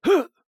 *跳跃时的声音*